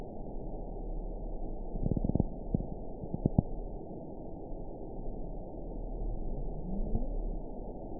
event 921647 date 12/14/24 time 11:32:50 GMT (4 months, 3 weeks ago) score 6.10 location TSS-AB04 detected by nrw target species NRW annotations +NRW Spectrogram: Frequency (kHz) vs. Time (s) audio not available .wav